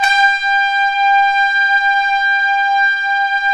Index of /90_sSampleCDs/Roland LCDP06 Brass Sections/BRS_Tpts mp)f/BRS_Tps Velo-Sw